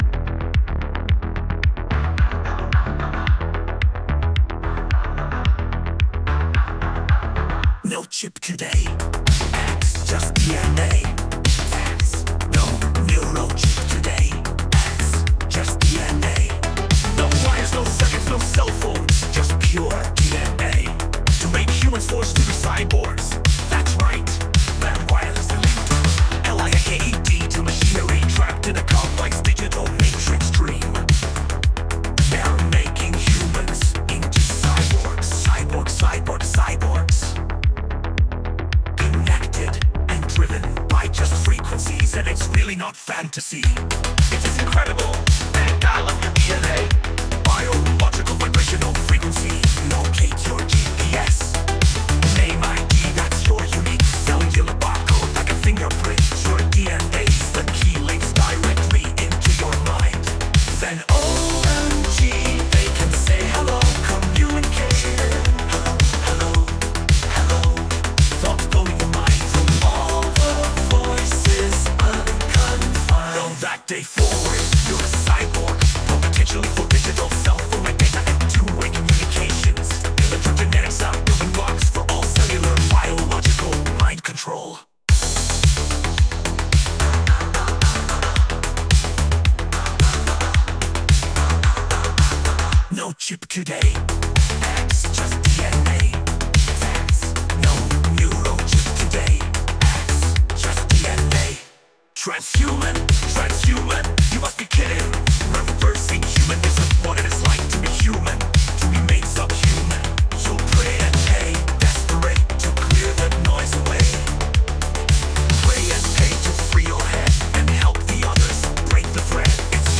Driving 1980's Disco-popmelodiccatchychorus in minor